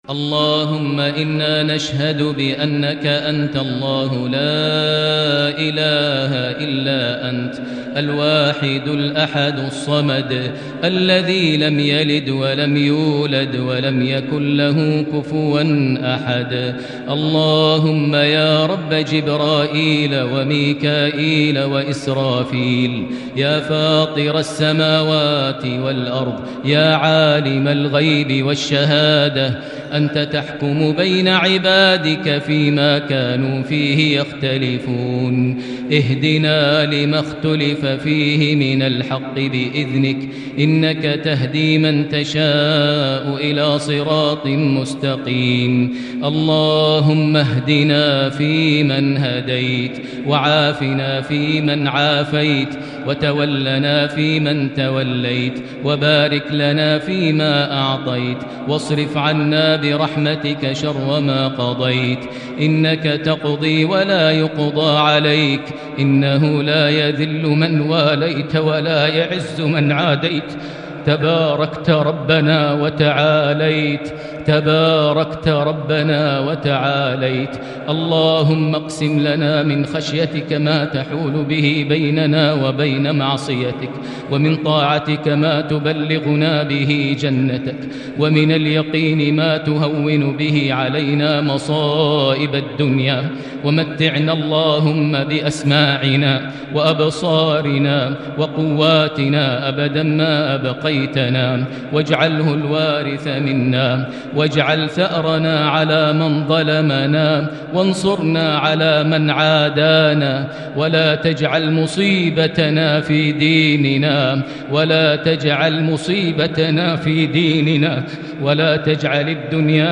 دعاء ليلة 11 رمضان 1441هـ > تراويح الحرم المكي عام 1441 🕋 > التراويح - تلاوات الحرمين